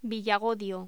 Locución: Villagodio
voz